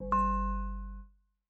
steeltonguedrum_g.ogg